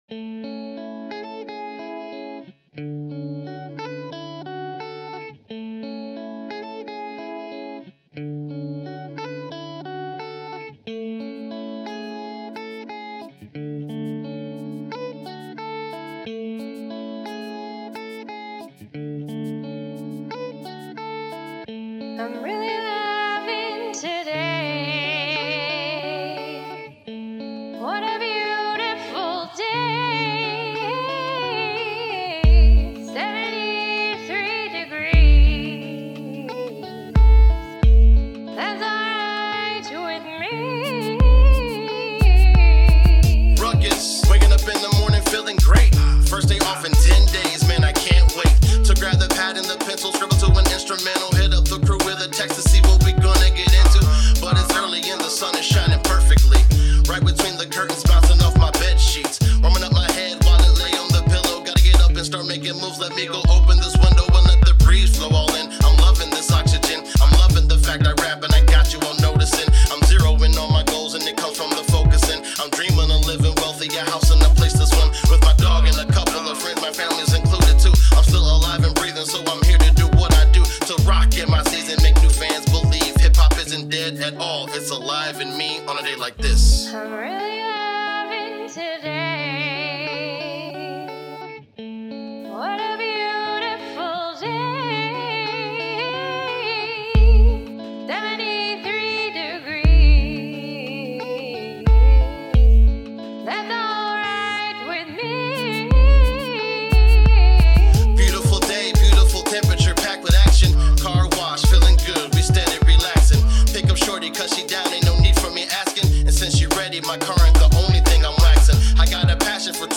Hiphop
Smooth and Easy Listening Hip-Hop for the current climate